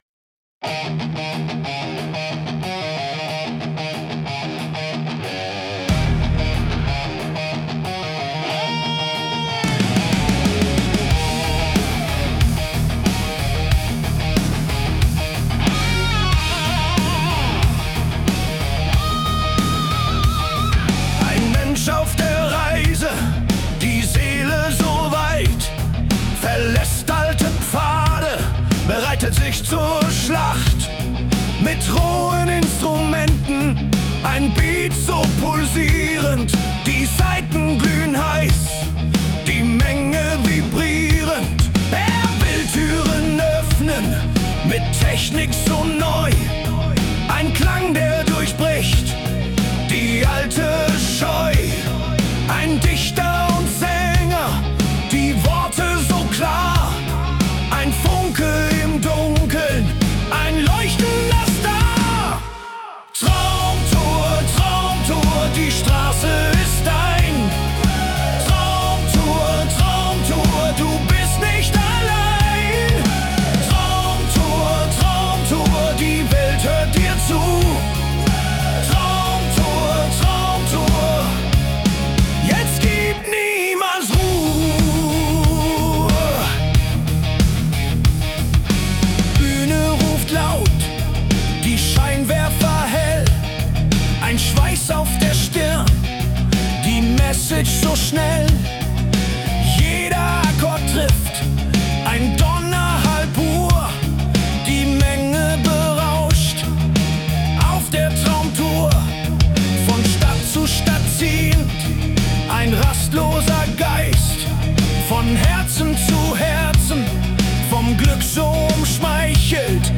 "Donnerhall pur... Menge pulsiert.... ewiges Feuer"... hier habe ich die Fantasie im Studio ein wenig spinnen lassen.
Ihr hört eine grinsend-protzige Beta-Version, die nur hier verschenkt wird.